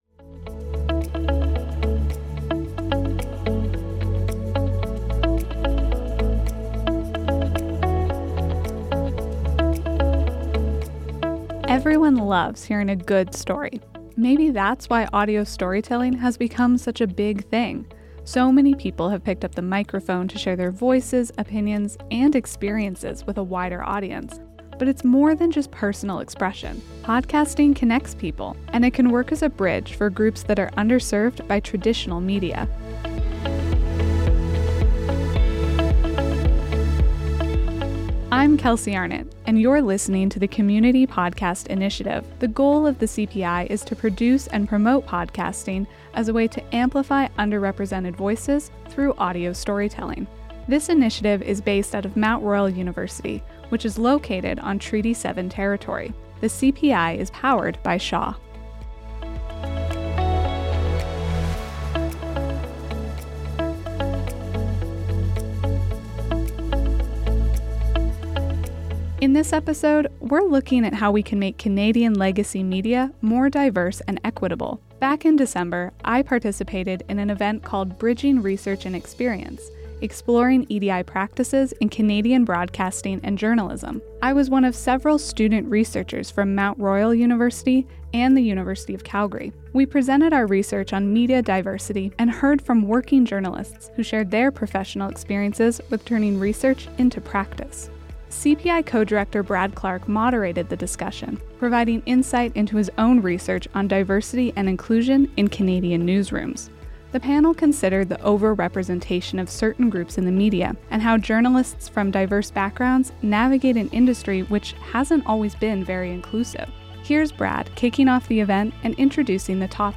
The panel also heard from working journalists about their experiences turning research into practice.